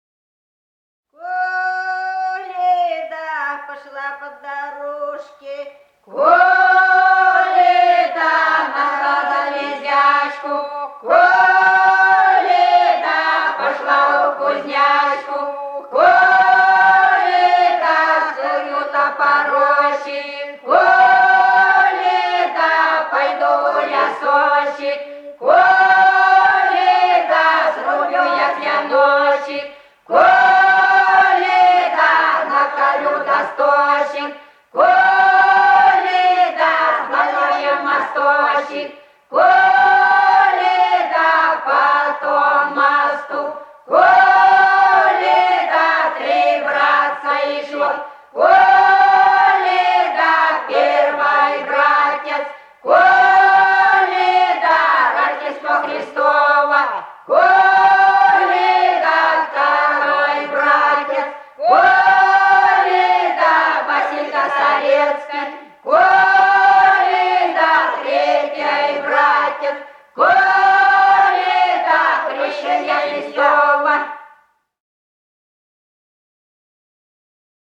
Голоса уходящего века (село Фощеватово) Коледа, пошла по дорожке (под Рождество)